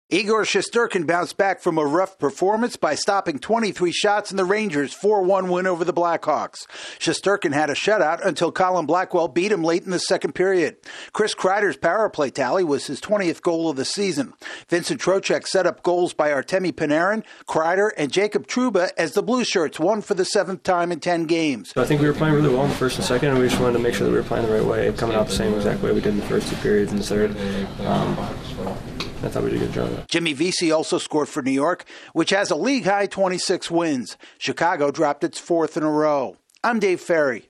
A Rangers netminder shines after allowing six goals on Tuesday. AP correspondent